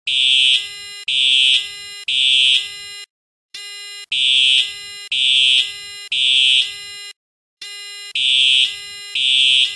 Fire Alarm Plex